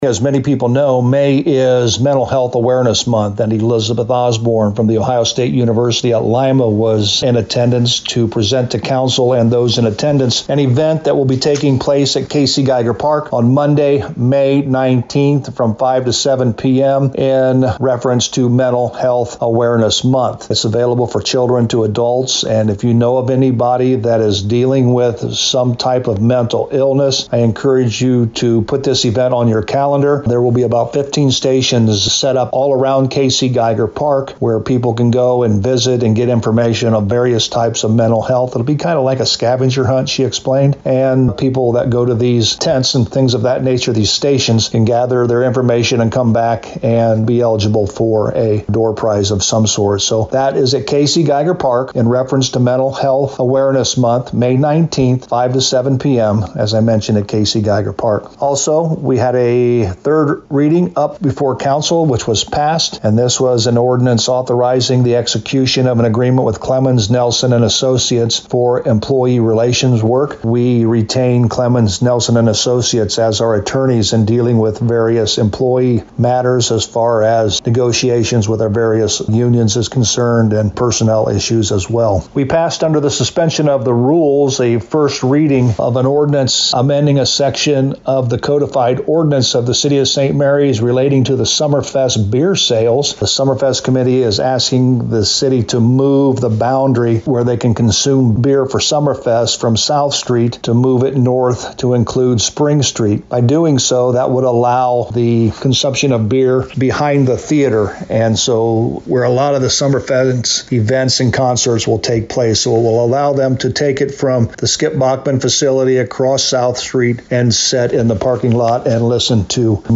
Local News
To hear a summary with St Marys Mayor Joe Hurlburt: